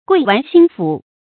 劌鉥心腑 注音： ㄍㄨㄟˋ ㄕㄨˋ ㄒㄧㄣ ㄈㄨˇ 讀音讀法： 意思解釋： 形容窮思苦索，刻意為之。